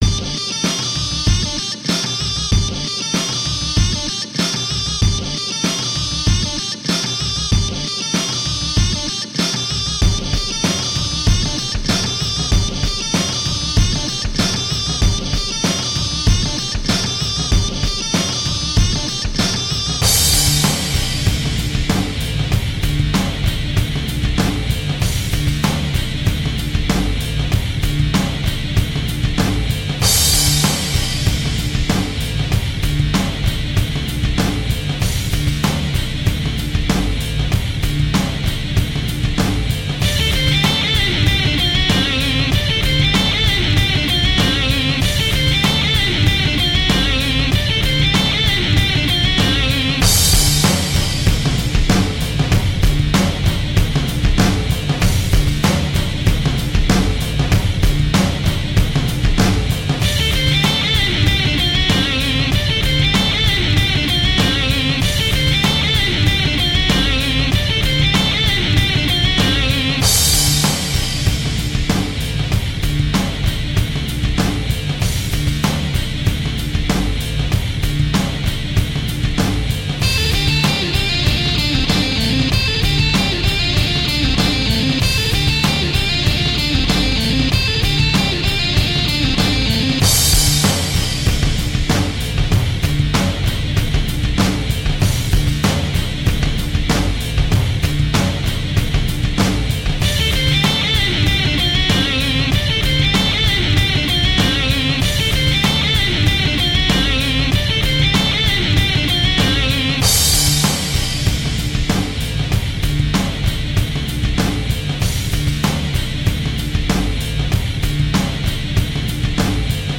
Instrumental hard rock.
Tagged as: Hard Rock, Metal, Instrumental